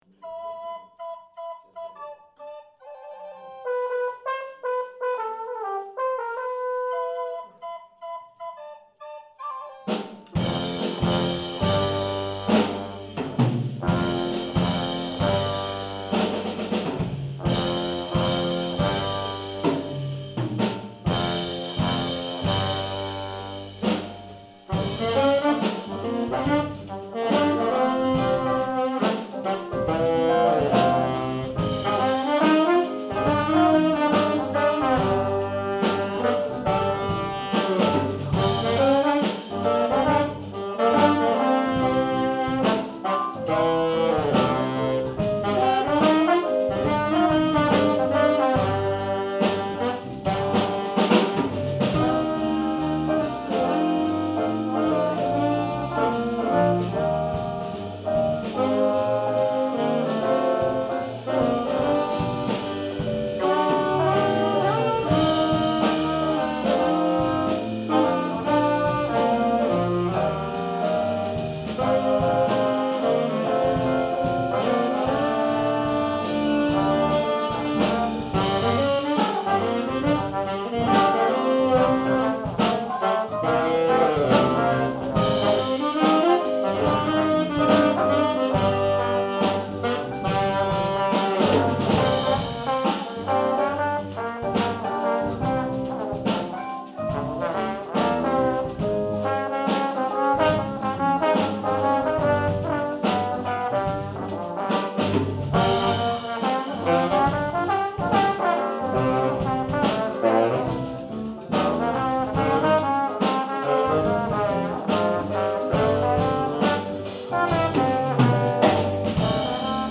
sax, tb, g, b, dr
· Genre (Stil): Jazz